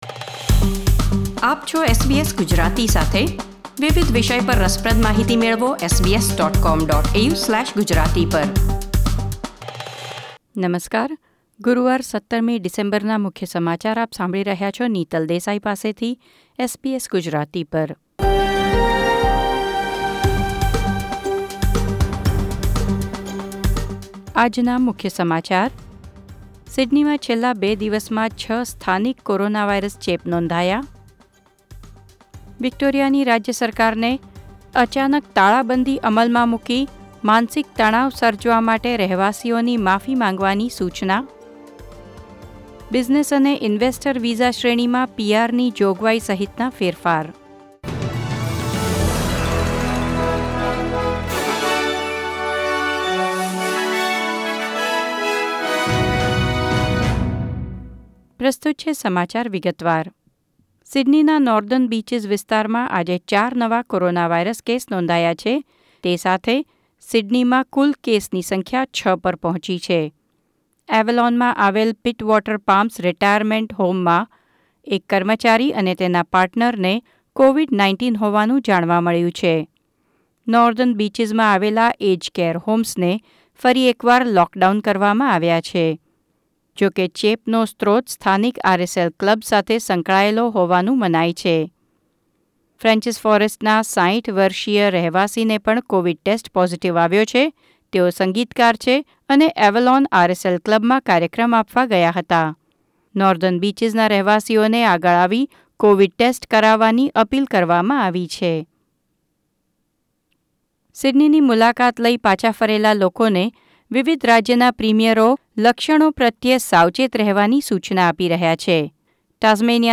SBS Gujarati News Bulletin 17 December 2020